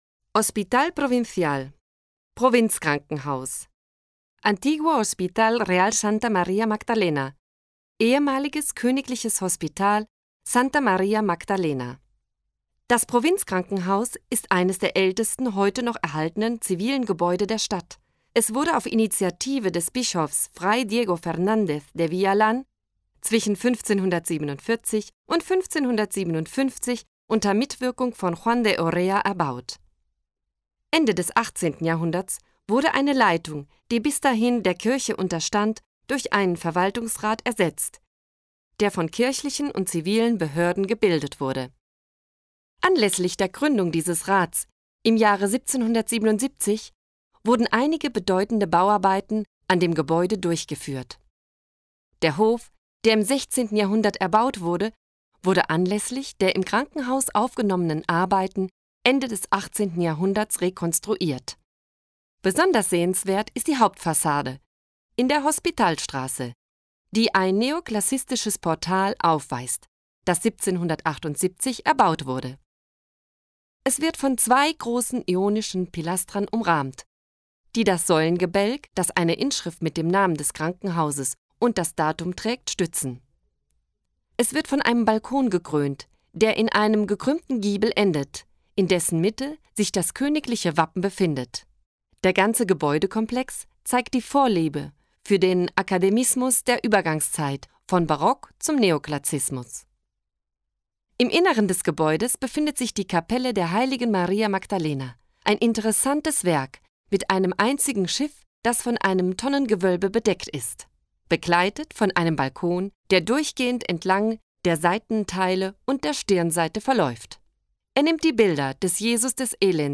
AUDIOGUIA-ALMERIA-ALEMAN-11-hospital-provincial.mp3